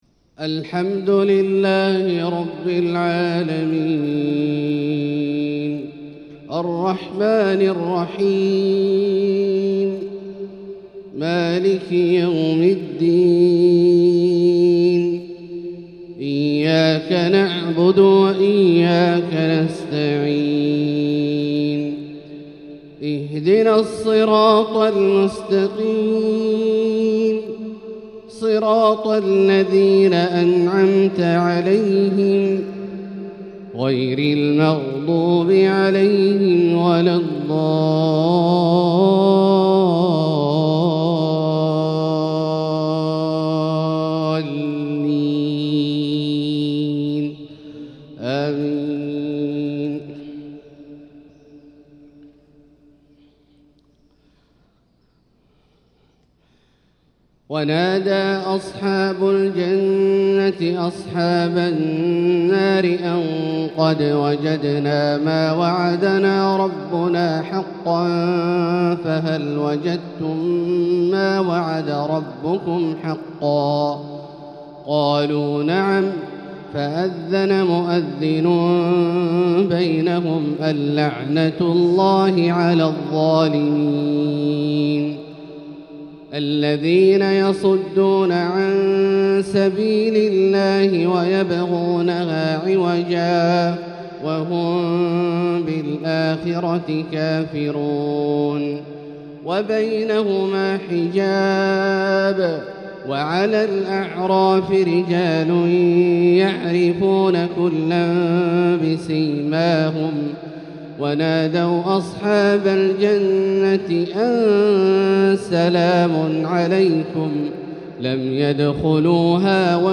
فجرية بياتية ماتعة من سورة الأعراف | الأحد 15 شعبان 1445هـ > ١٤٤٥هـ > الفروض - تلاوات عبدالله الجهني